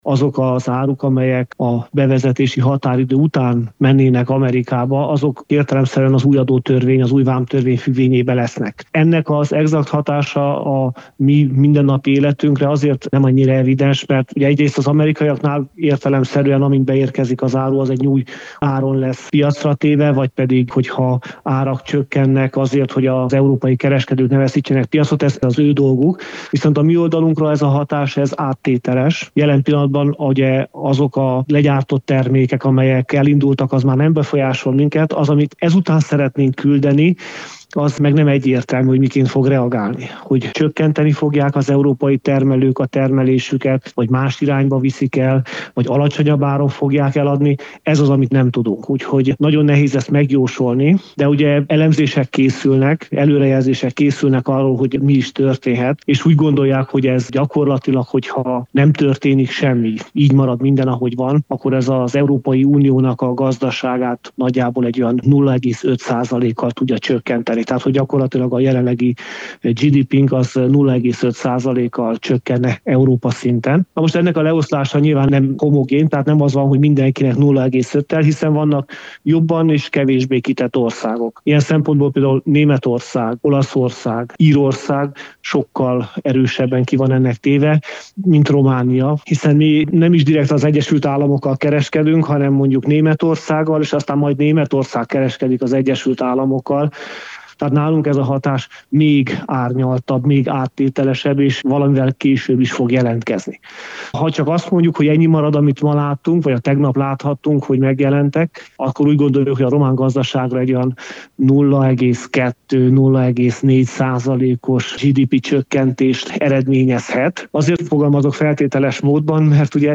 Donald Trump amerikai elnök vámtarifákat jelentett be, az EU-ra 20 százalékosat. Közgazdászt kérdeztünk ennek hatásairól.